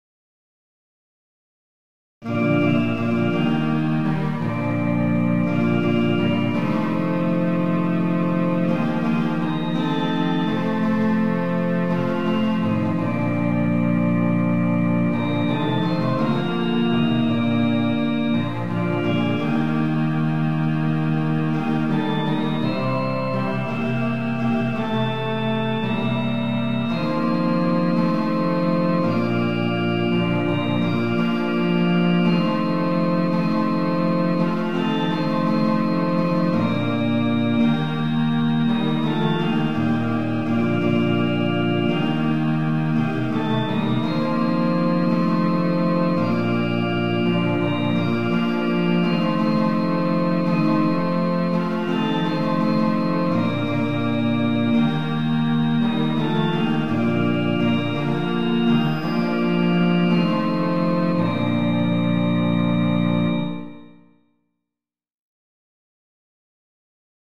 Organ & Clarinet Play the mp3 file